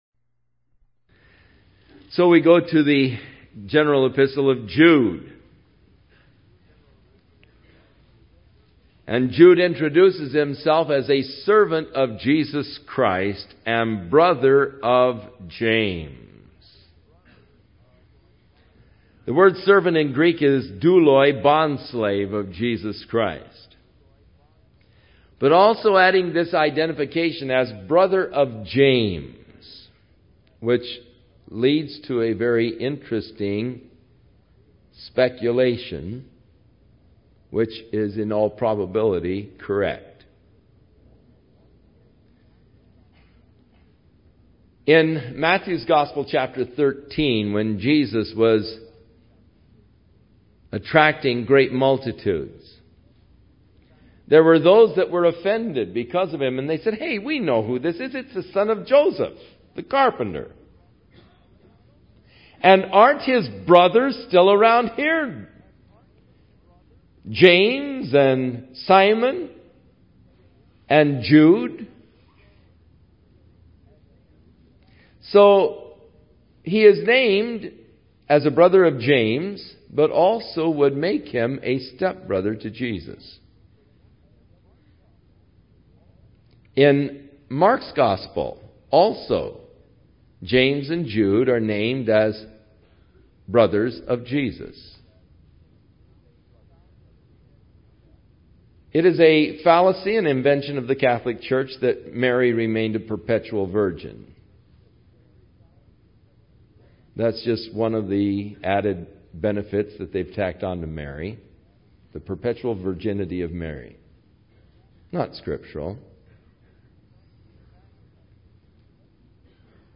01 Verse by Verse Teaching